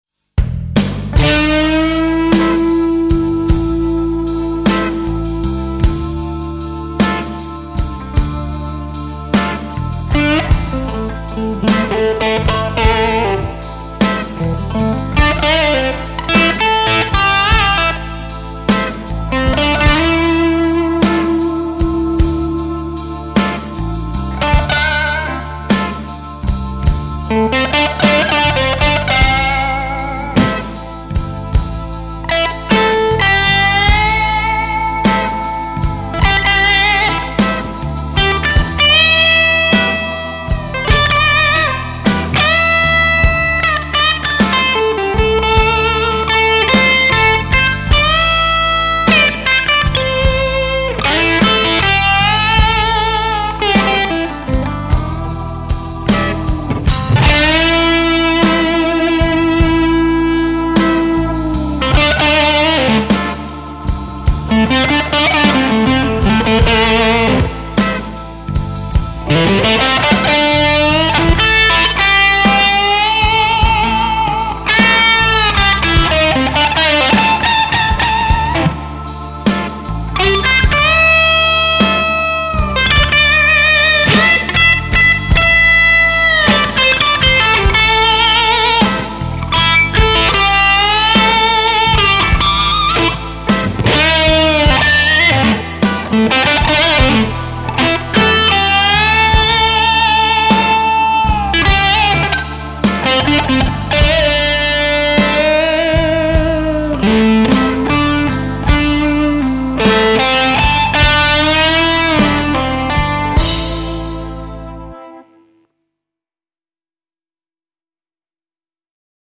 Da ich nicht ausschließen konnte, daß ich die Regler am Womanizer seit den letzten Clips nicht bewegt hatte, habe ich sowohl einen Take mit dem Originalpickup als auch einen mit dem neuen P90 eingespielt.
Hier ist erstmal der neue Pickup:
P90-Test - Tokai Junior - Zhangliqun P90 - Damage Control Womanizer